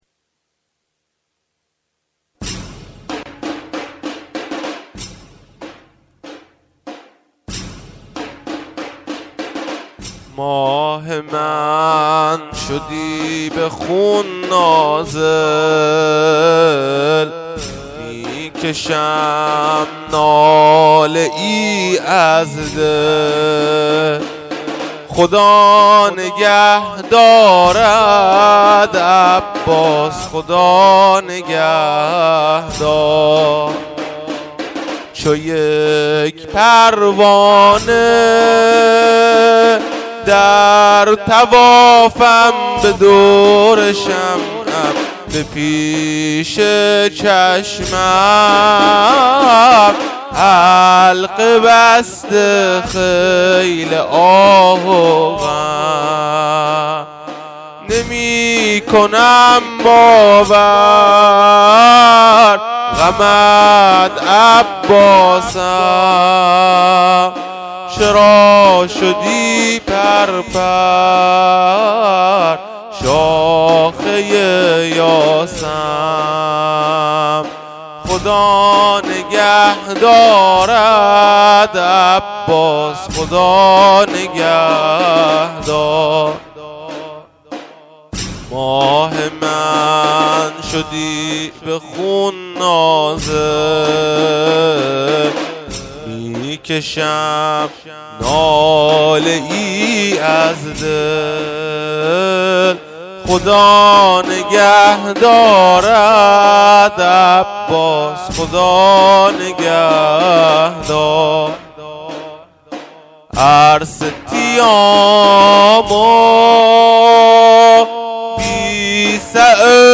تاسوعای ۱۳۹۷
مسجد سیدصالح